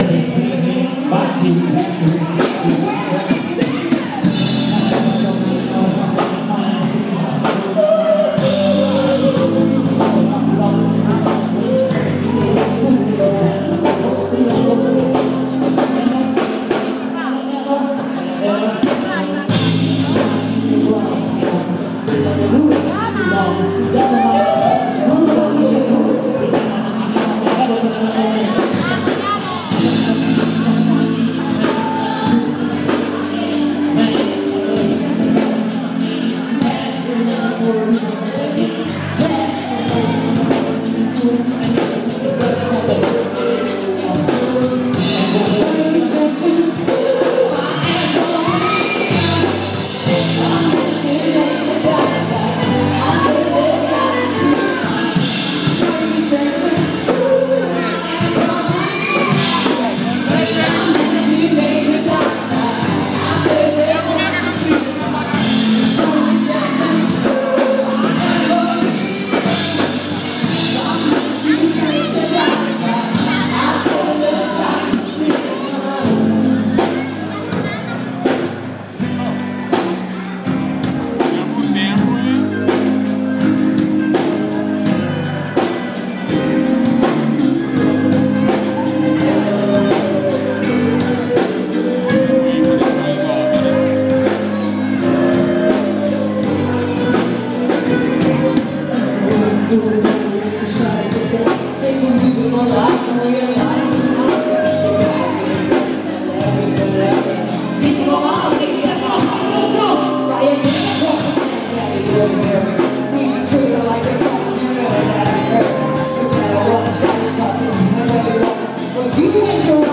Live in Barga - second night - Piazza Angelio